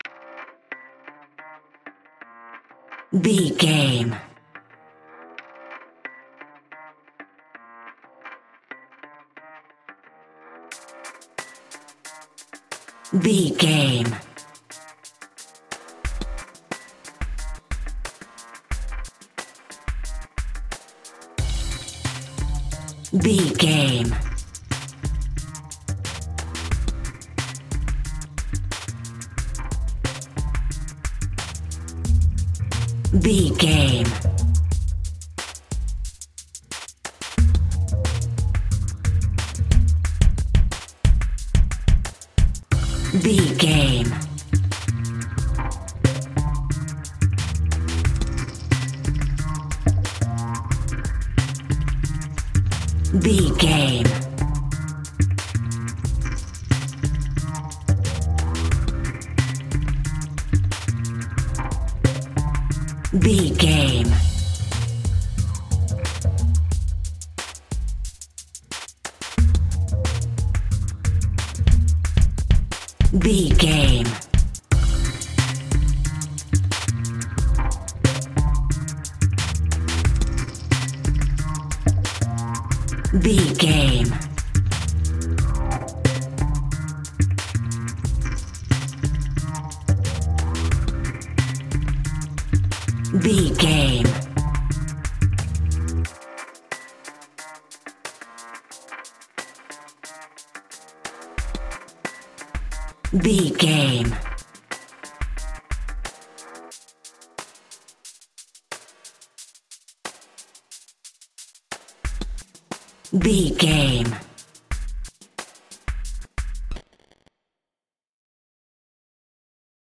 Ionian/Major
scary
tension
ominous
dark
suspense
haunting
eerie
bass guitar
drum machine
Drum and bass
break beat
electronic
sub bass
synth
mysterious
industrial music
synth leads